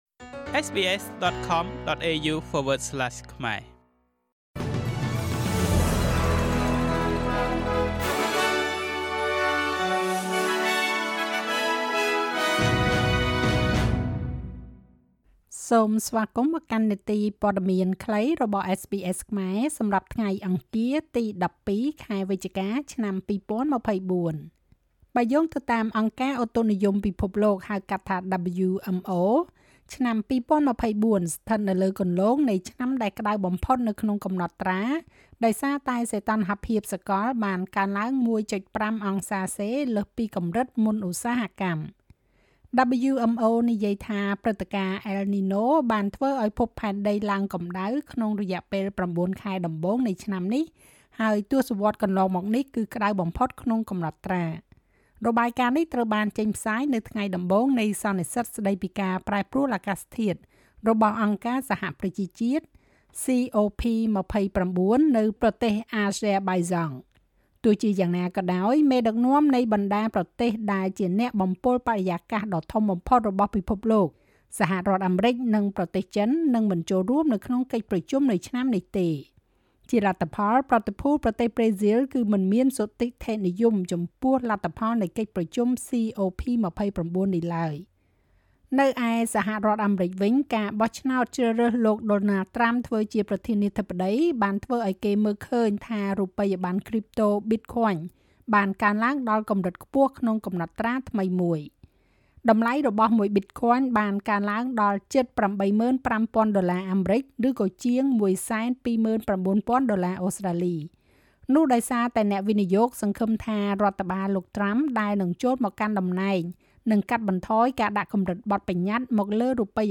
នាទីព័ត៌មានខ្លីរបស់SBSខ្មែរ សម្រាប់ថ្ងៃអង្គារ ទី១២ ខែវិច្ឆិកា ឆ្នាំ២០២៤